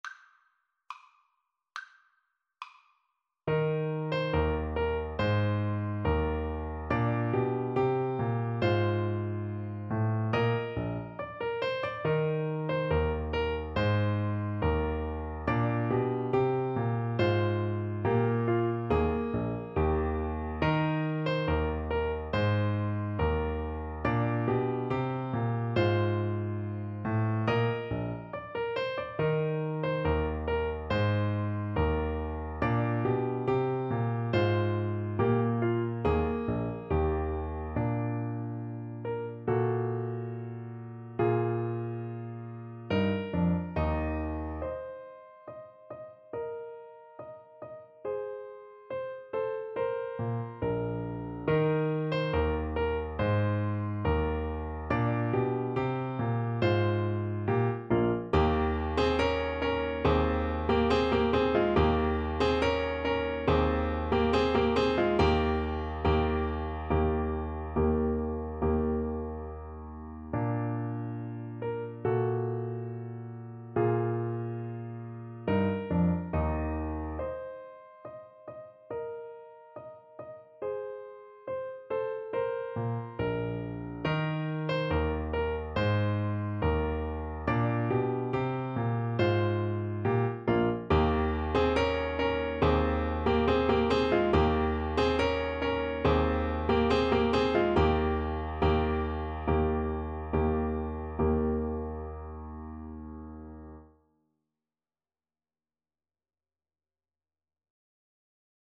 2/4 (View more 2/4 Music)
Classical (View more Classical Saxophone Music)